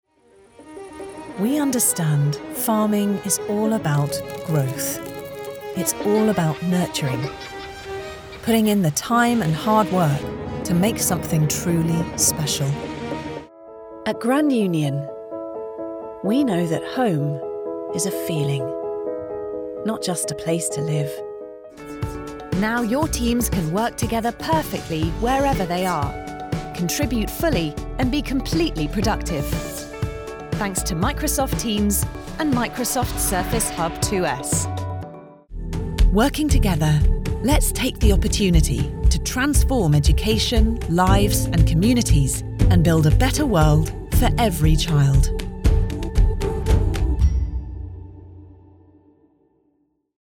Inglés (Británico)
Comercial, Natural, Accesible, Versátil, Cálida
Corporativo